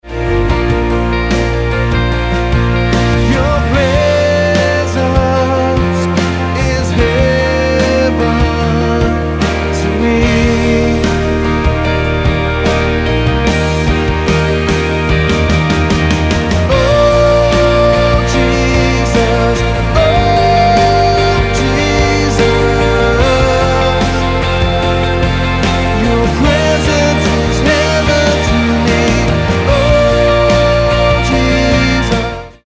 Bb